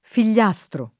[ fil’l’ #S tro ]